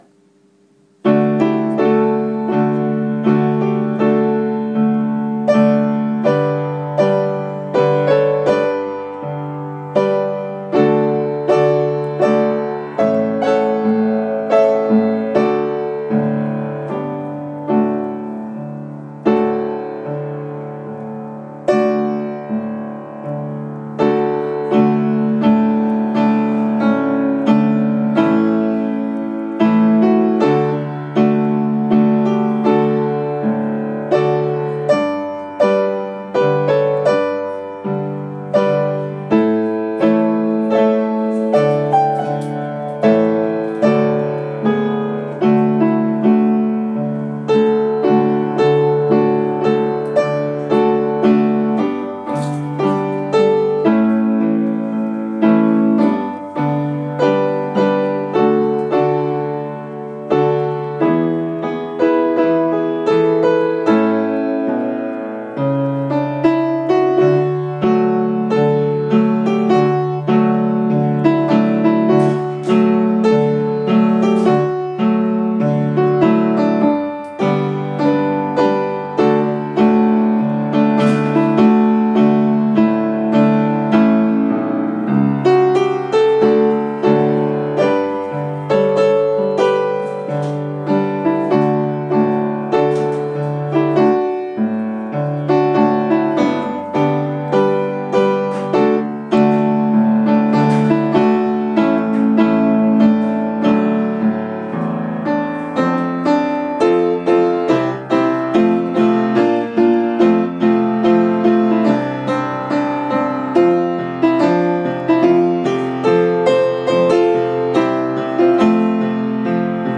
piano: welsh medley